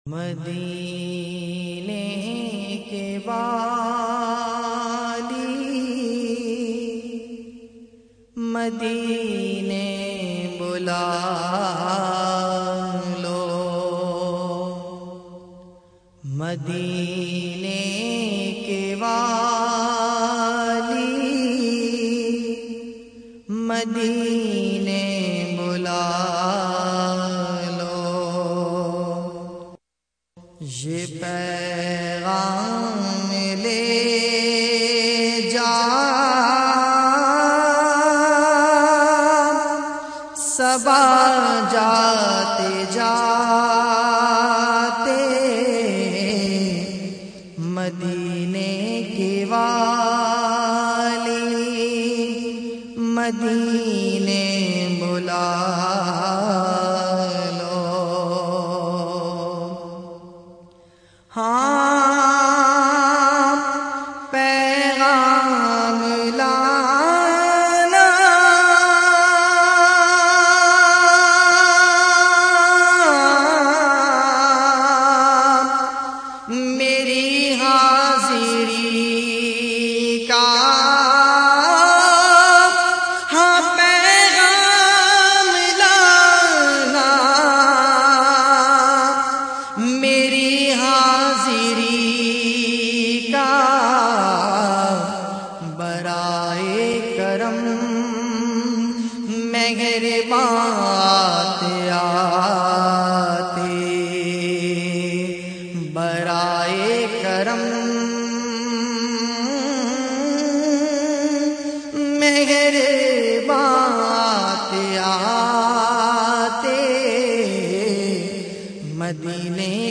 urdu naats